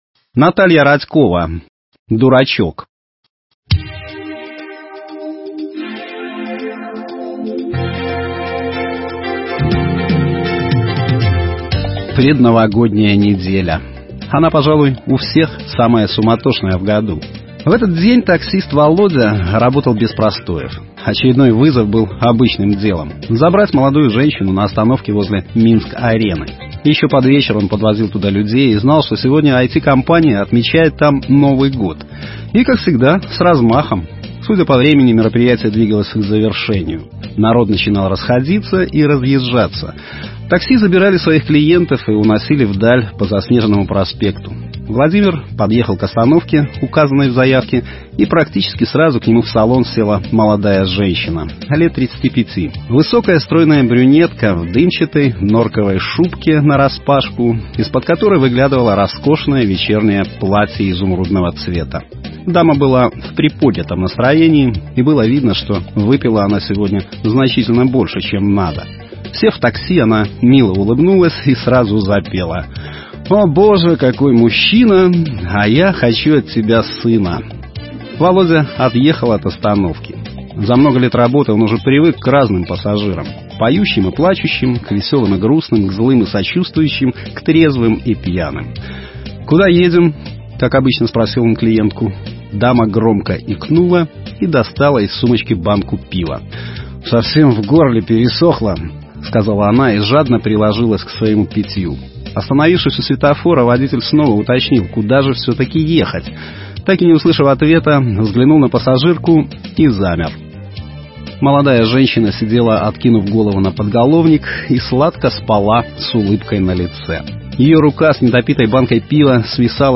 Аудиокниги
Аудио-Рассказы